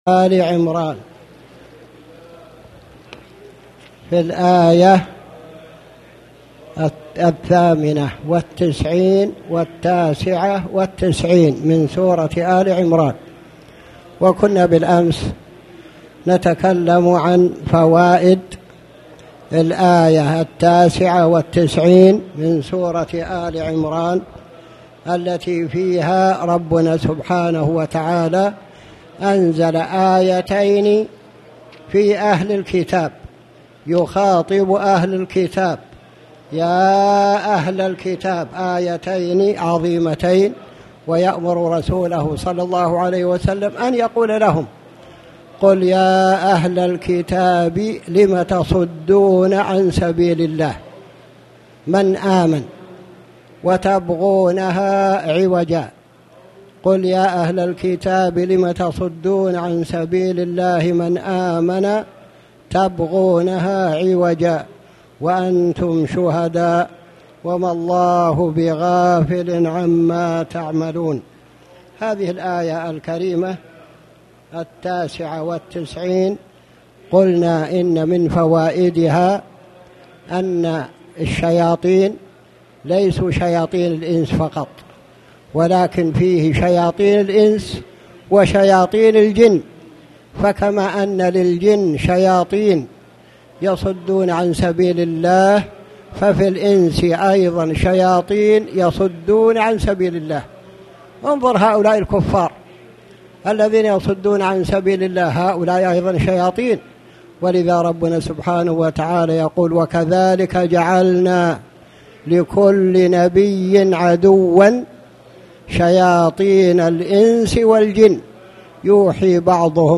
تاريخ النشر ١٥ ربيع الأول ١٤٣٩ هـ المكان: المسجد الحرام الشيخ